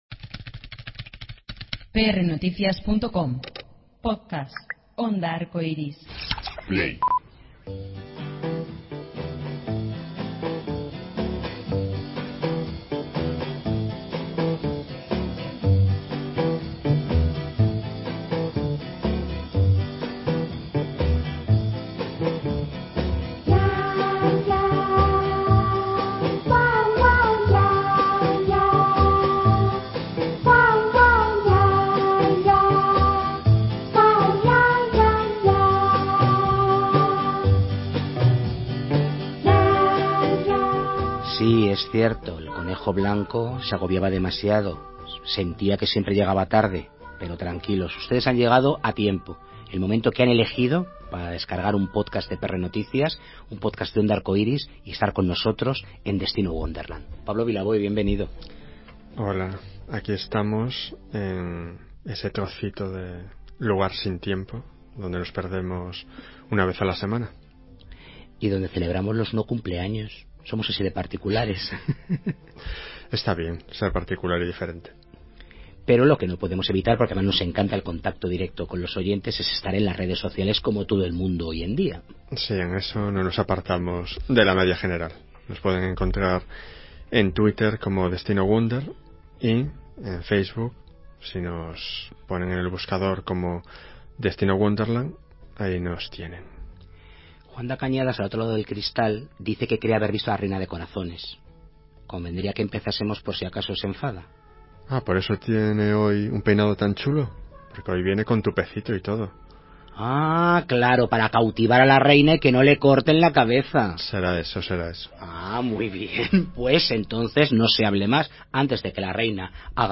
Una de las actrices más prestigiosas de nuestro país, Susi Sánchez, nos recibe en el Matadero de Madrid para charlar sobre su vida en el teatro... ¡y en la televisión!